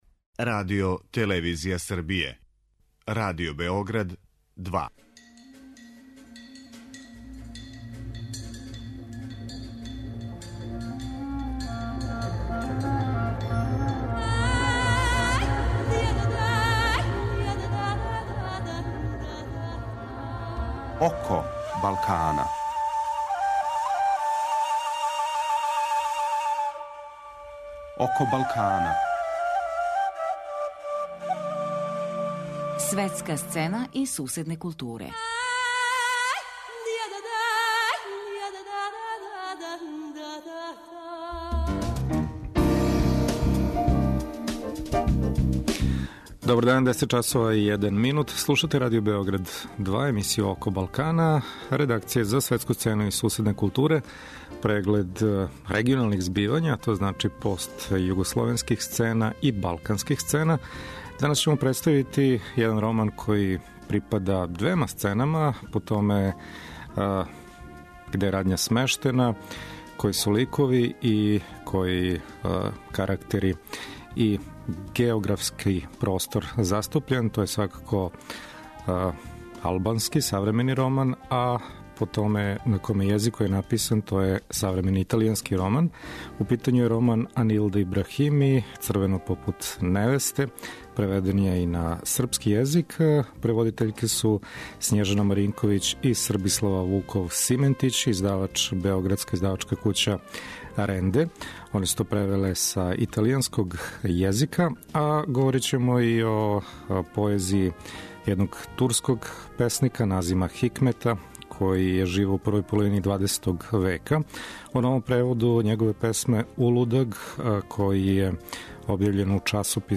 Саговорница нам је једна од преводитељки овог романа са италијанског језика